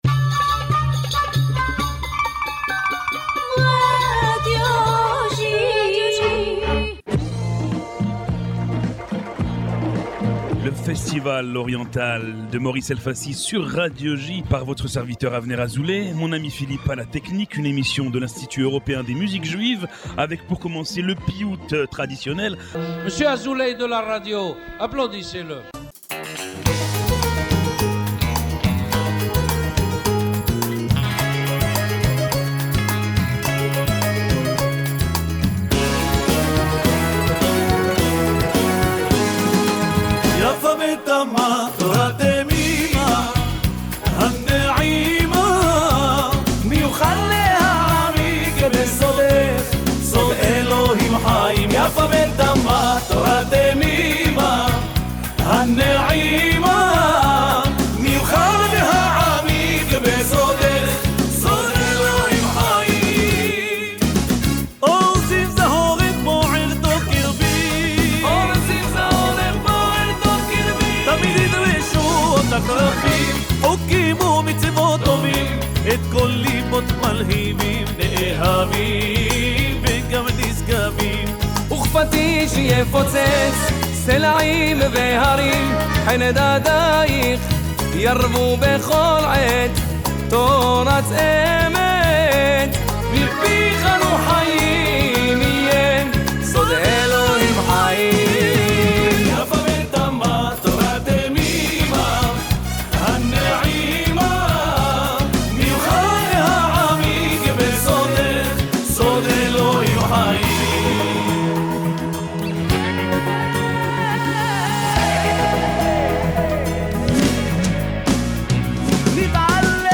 Broadcasted every Monday on Radio J (94.8 FM), « The Oriental Festival » is a radio program from the European Institute of Jewish Music entirely dedicated to Eastern Music.